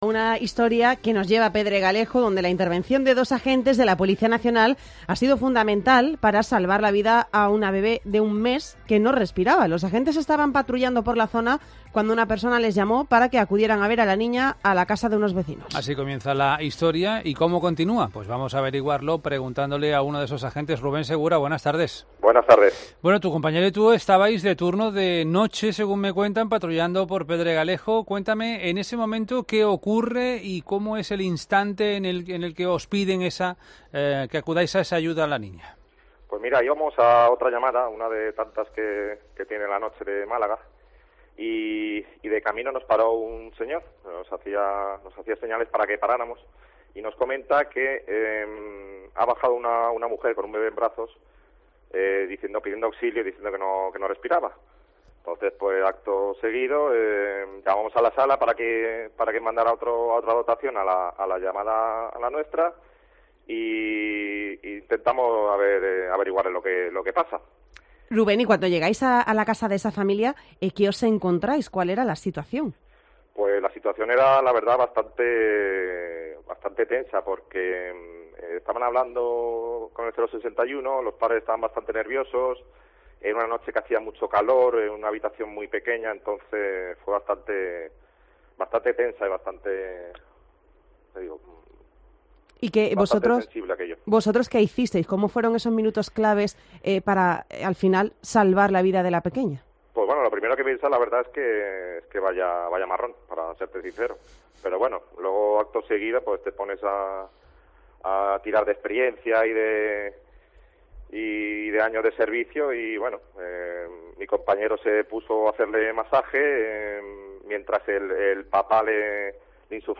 Uno de los agentes relata en COPE Málaga cómo socorrieron a la pequeña, de tan solo un mes de vida, en el barrio de Pedralejo